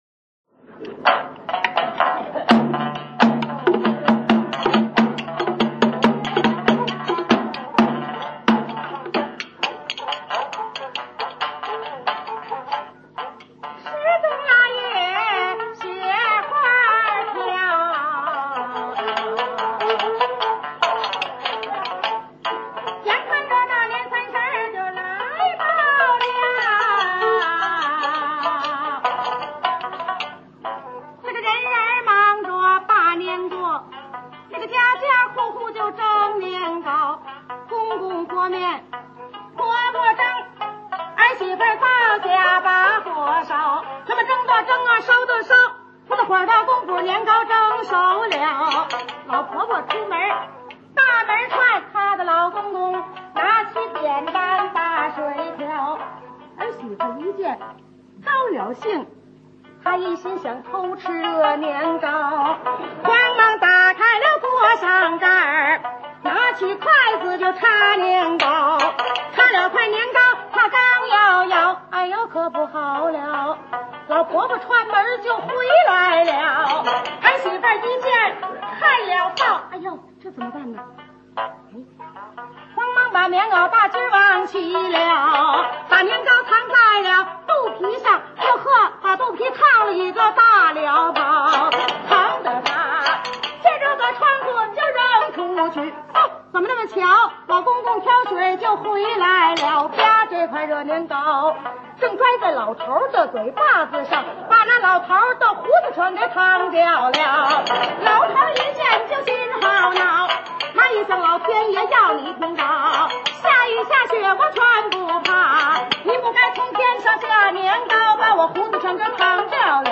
蘊生自民間土壤的說唱藝人
十八段原味酣暢的曲藝聲腔